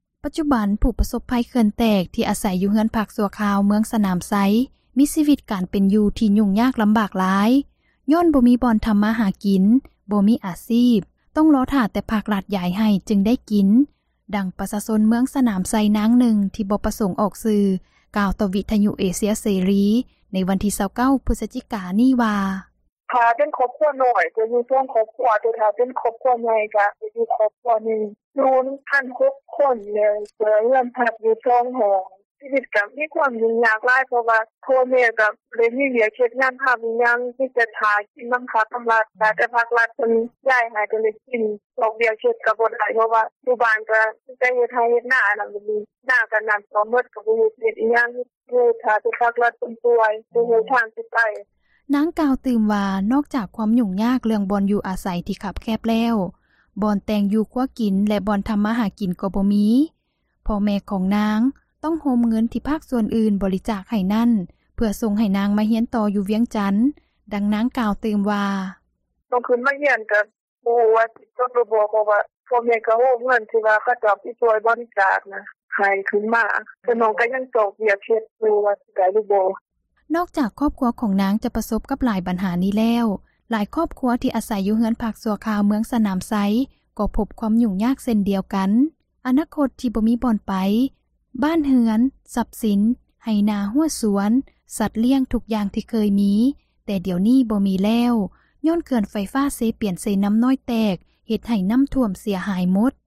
ປັດຈຸບັນ ຜູ້ປະສົບພັຍເຂື່ອນ ທີ່ອາສັຍຢູ່ເຮືອນພັກຊົ່ວຄາວ ເມືອງສນາມໄຊ ມີຊີວິດການເປັນຢູ່ຫຍຸ້ງຍາກ ລຳບາກຫຼາຍ ຍ້ອນບໍ່ມີບ່ອນ ທຳມາຫາກິນ, ບໍ່ມີອາຊີບ ຕ້ອງລໍຖ້າແຕ່ ພັກ-ຣັຖ ຢາຍໃຫ້ຈຶ່ງໄດ້ກິນ. ດັ່ງປະຊາຊົນ ເມືອງສນາມໄຊ ນາງນຶ່ງທີ່ບໍ່ປະສົງ ອອກຊື່ ກ່າວຕໍ່ ວິທຍຸເອເຊັຍເສຣີ ໃນວັນທີ 29 ພຶສຈິກາ ນີ້ວ່າ: